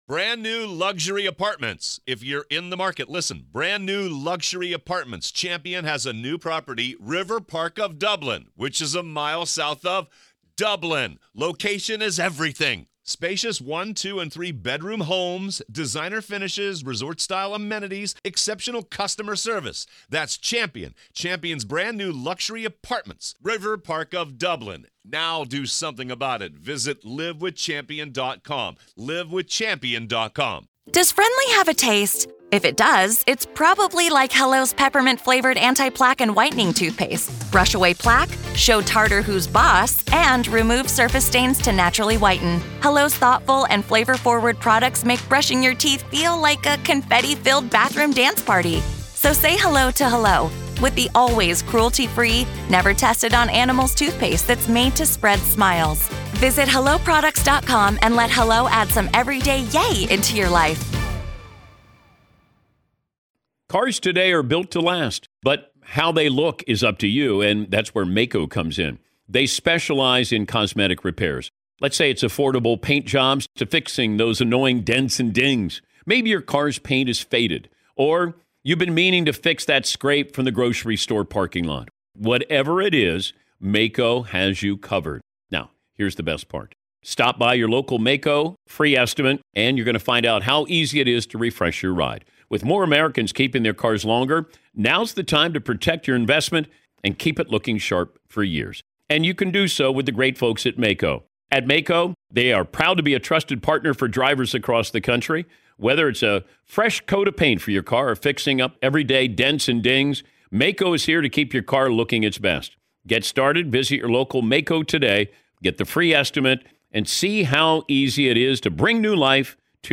Court Audio-NV v. Robert Telles DAY 1 Part 2